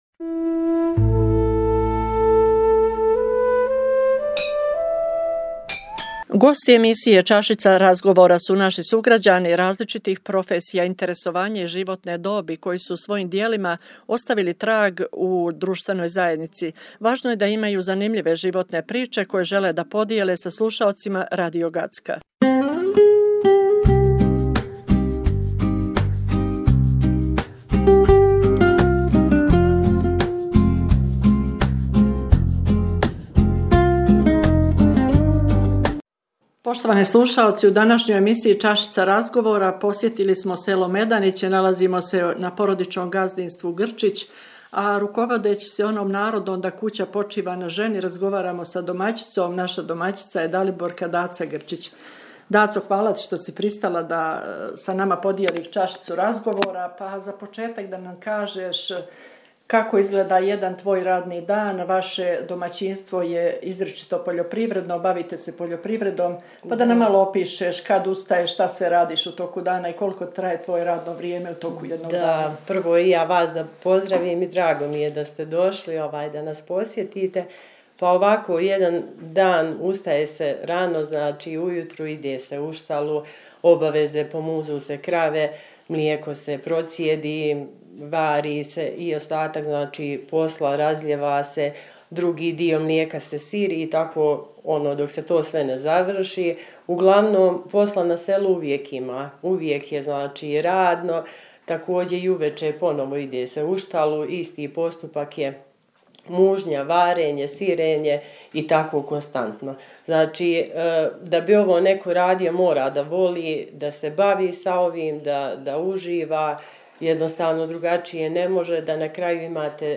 У емисији „Чашица разговора“ говоримо о животу жене на селу.